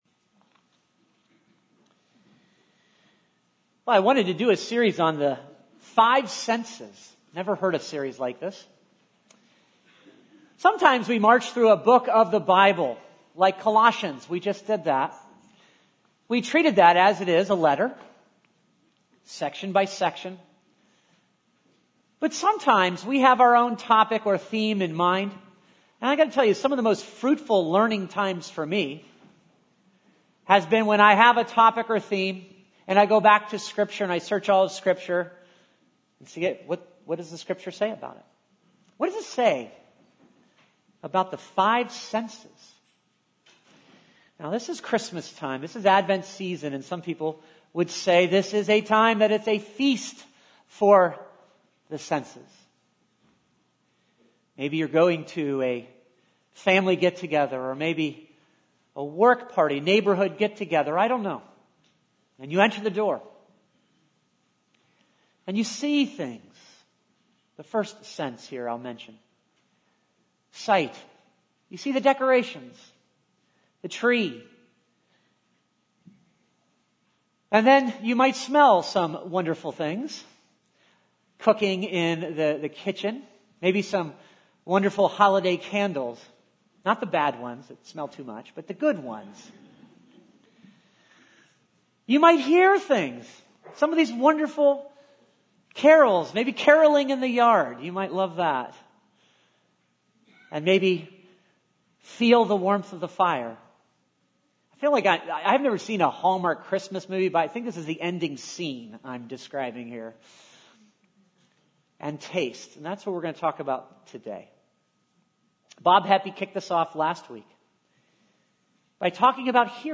A message from the series "Advent 2024."